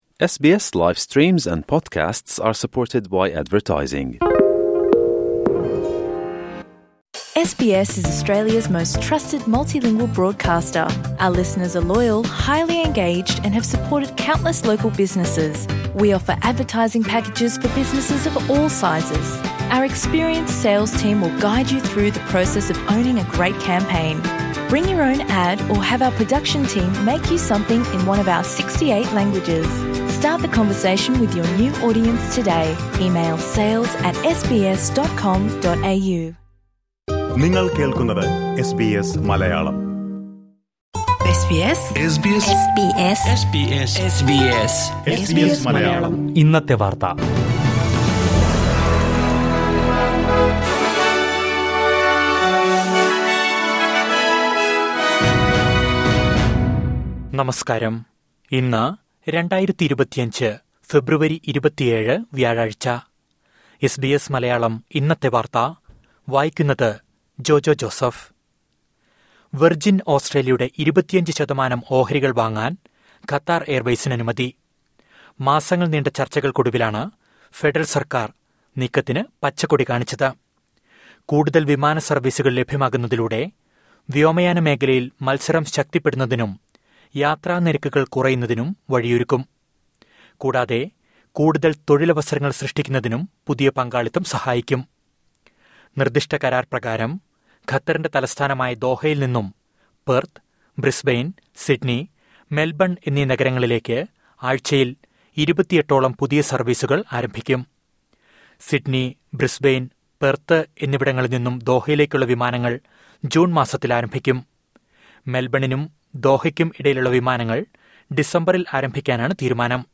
2025 ഫെബ്രുവരി 27ലെ ഓസ്‌ട്രേലിയയിലെ ഏറ്റവും പ്രധാന വാര്‍ത്തകള്‍ കേള്‍ക്കാം...